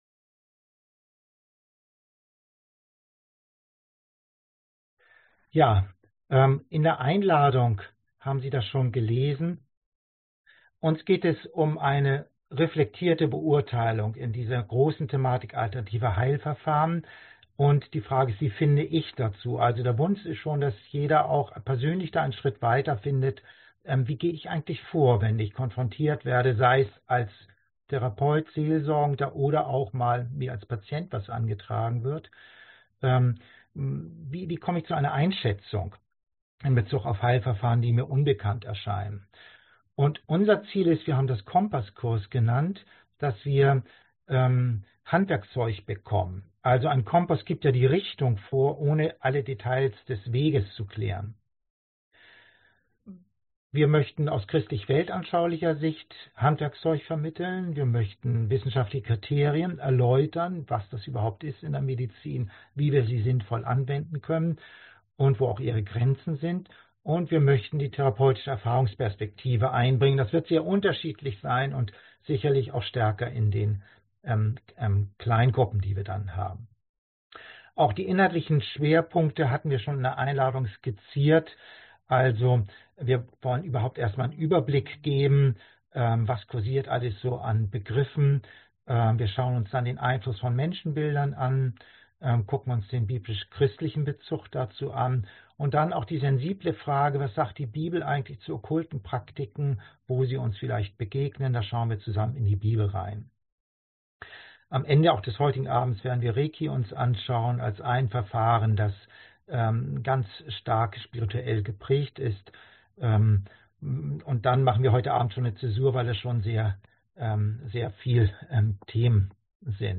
3 Interaktive Seminarabende | Online